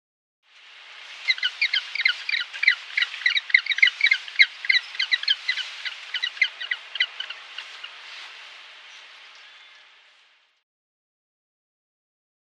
Penguin Chirps. Beach Penguins Chirp With Surf Noise In The Background. Medium Perspective.